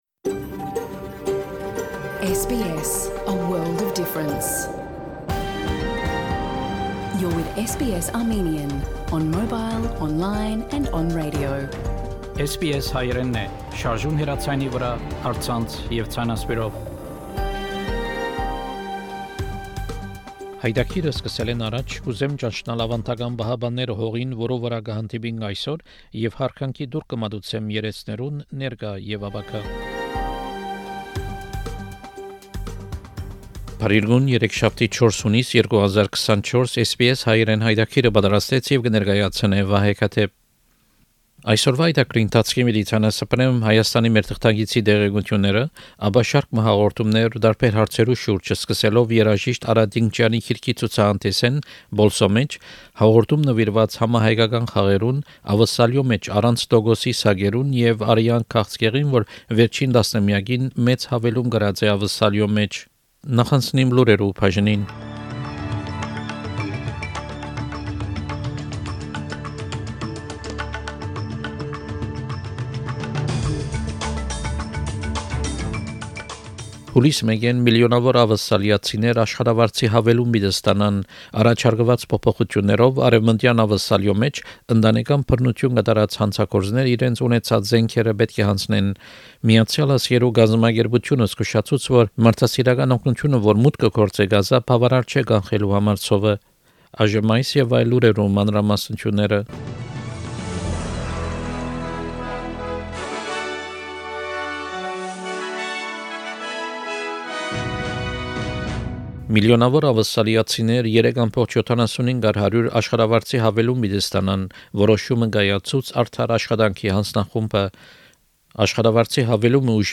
SBS Armenian news bulletin – 4 June 2024
SBS Armenian news bulletin from 4 June program.